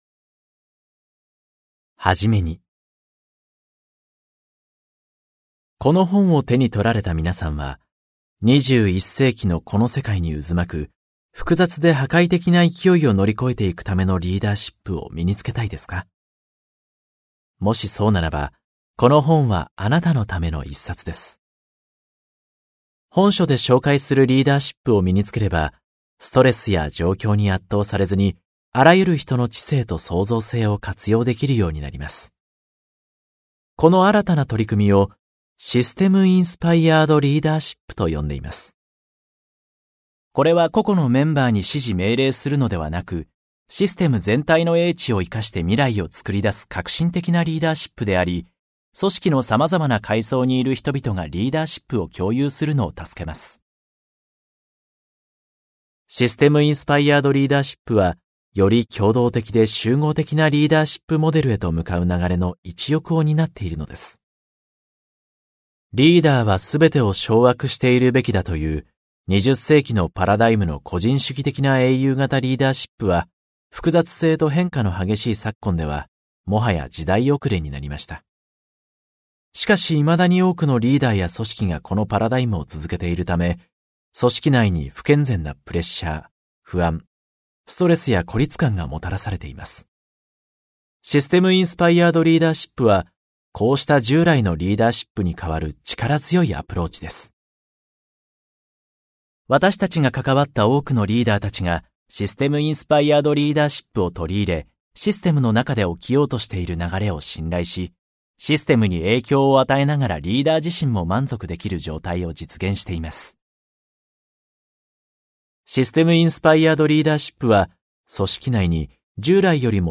[オーディオブック] 人と組織の進化を加速させる システム・インスパイアード・リーダーシップ